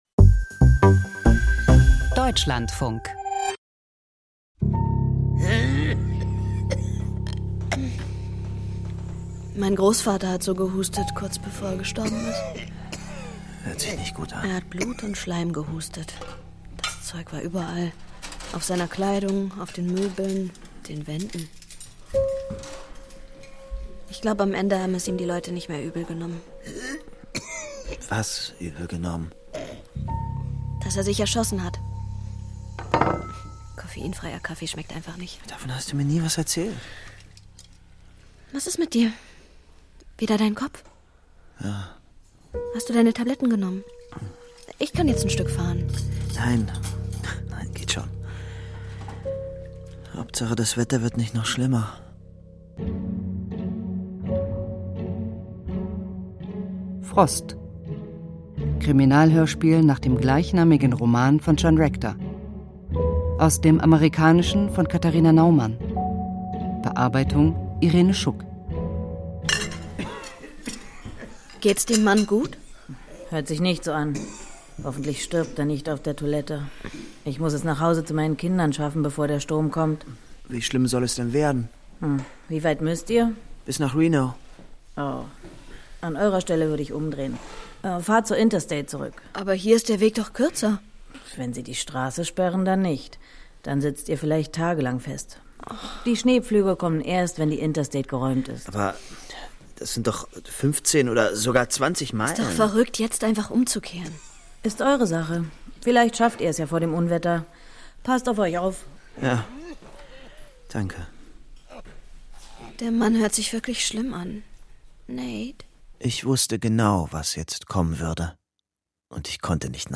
Aus dem Podcast Kriminalhörspiel Podcast abonnieren Podcast hören Podcast Krimi Hörspiel Die ganze Welt des Krimis in einem Podcast: Von Agatha Christie bis Donna Leon und Kommissar...